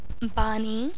Pronunciation